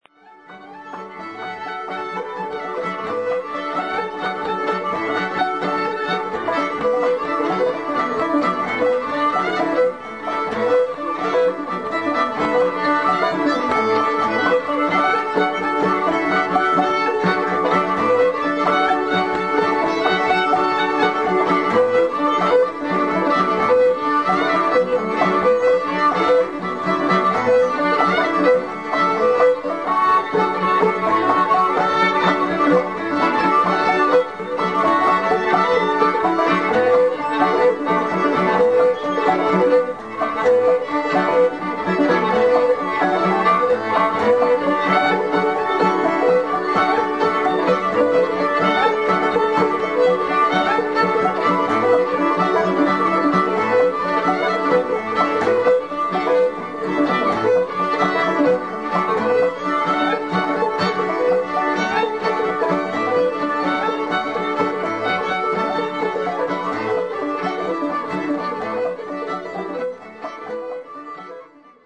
Fiddle
Banjo